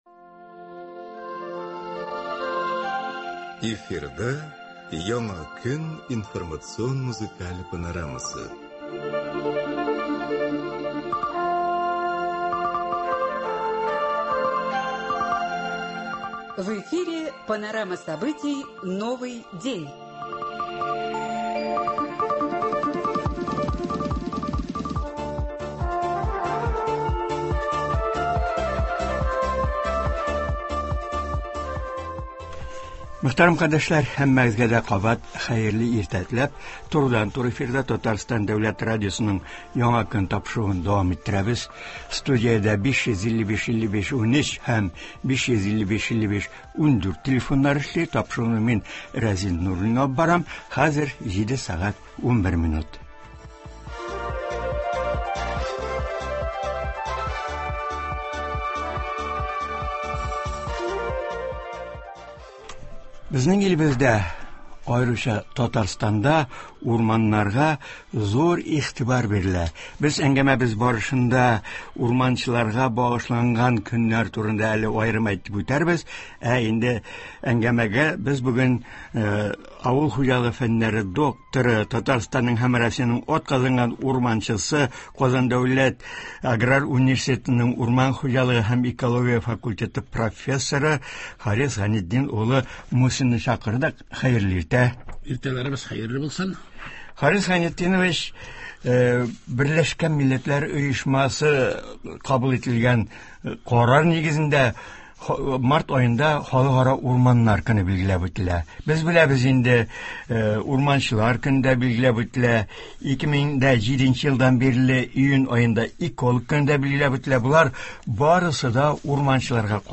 Туры эфир (03.04.24)